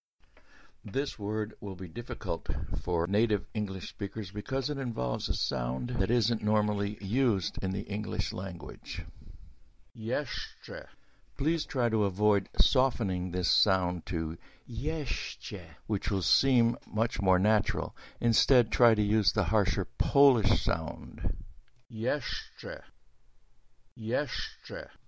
Polish Words -- Baritone Voice
Jeszcze (YESZ - czeh)
NOTE: The retention of the Polish phonemes /sz/ and /cz/ is deliberate because they are harsher and stronger than the similar, but not exact, English /sh/ and /ch/ phonemes.